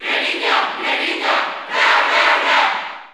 Crowd cheers (SSBU) You cannot overwrite this file.
Greninja_Cheer_Spanish_NTSC_SSB4_SSBU.ogg